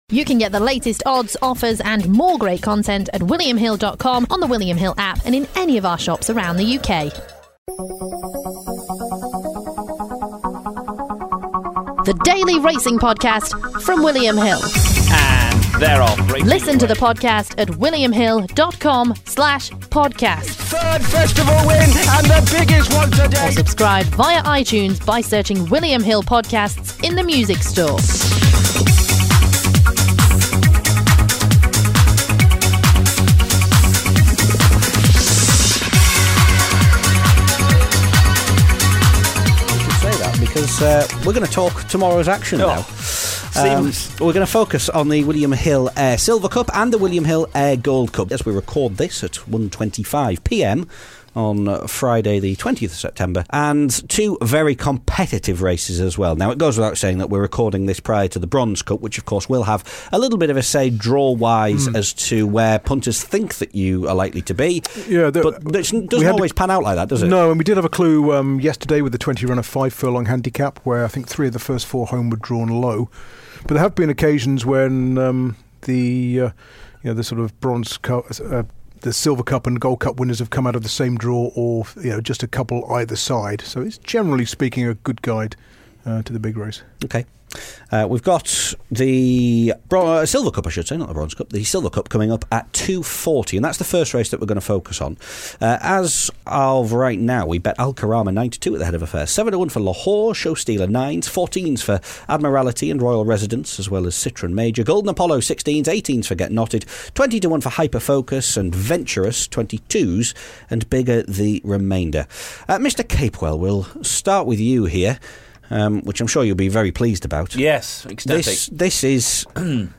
The trio look ahead to two competitive sprints on Saturday, namely the William Hill Ayr Silver Cup and the William Hill Ayr Gold Cup.